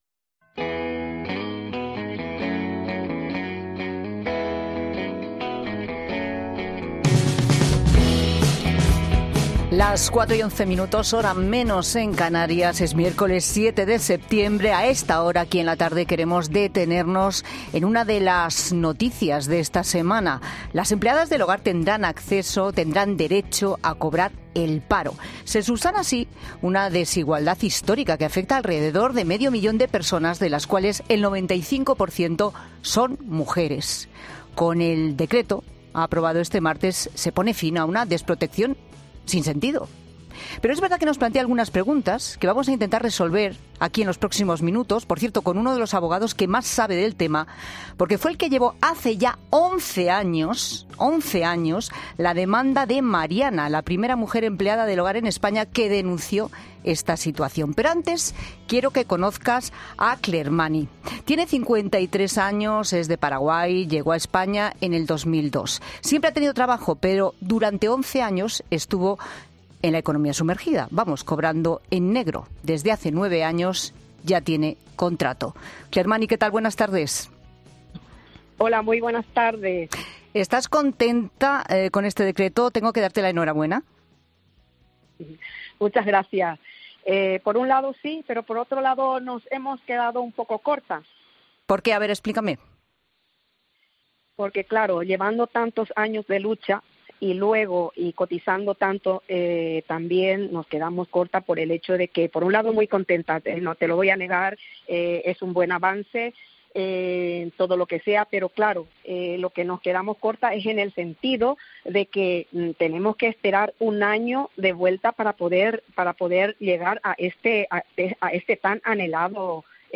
En La Tarde de COPE, hemos hablado con los protagonistas de este asunto: las empleadas del hogar.